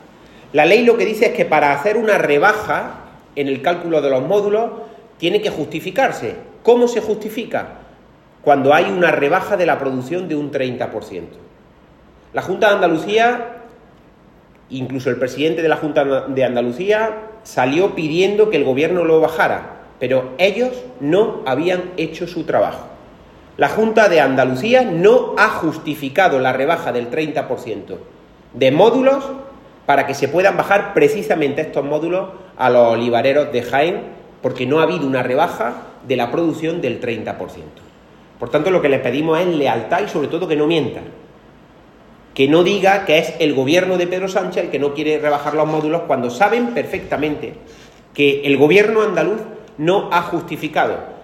En rueda de prensa, Latorre puso ejemplos concretos que demuestran su afirmación y que contradicen los bulos propagados por el PP y alguno de sus alcaldes.
Cortes de sonido